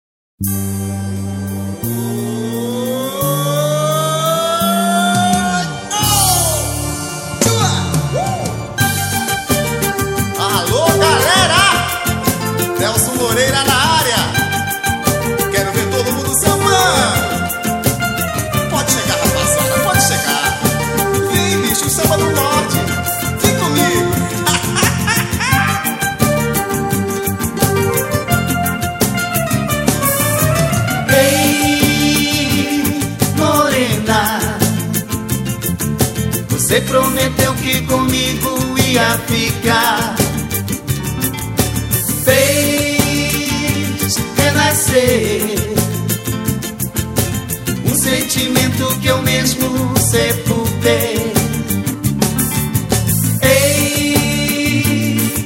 Pagode.